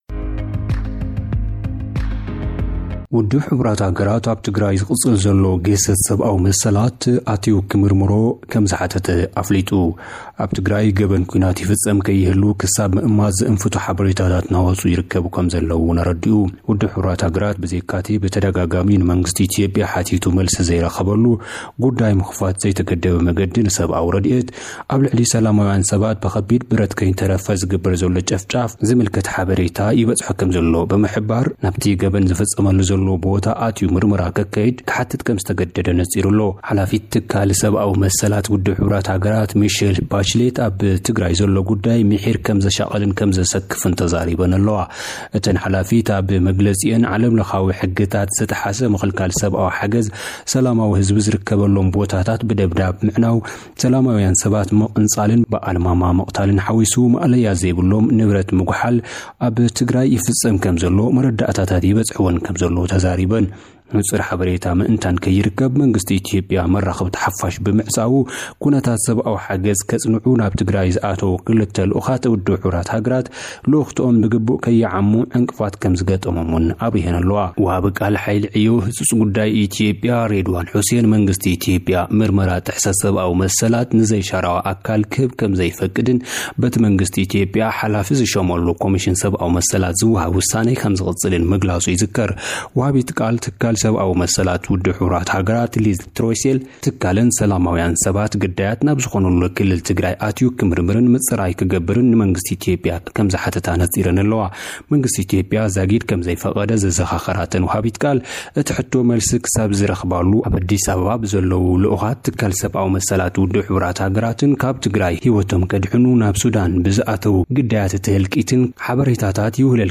ኢትዮጵያ ምስ ሱዳን ኣብ ካርቱም ዝነበራ ዝርርብ ብዘይፍረ ምብታኑ ንሱዳን ከሲሳ። (ጸብጻብ)